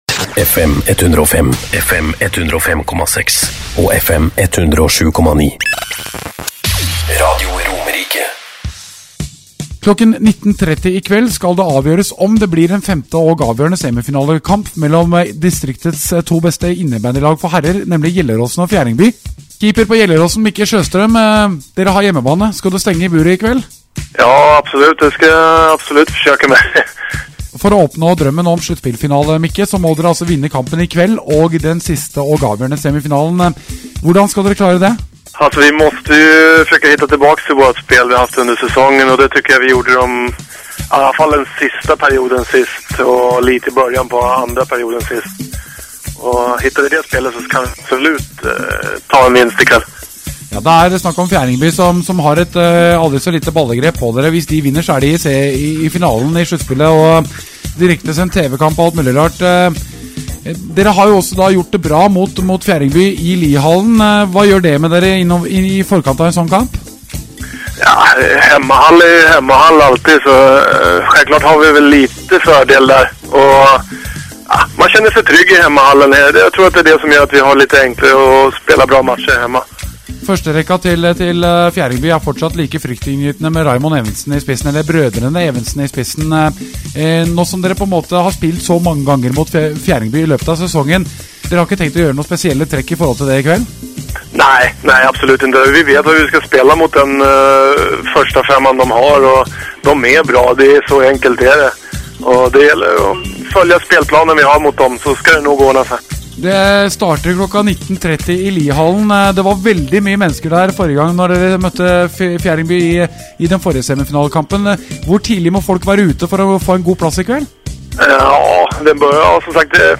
Inför semifinal nr4 på hemmaplan, var jag återigen med i norsk radio, “Lyssna på intervjun här under!”
for_semifinal_nr4.mp3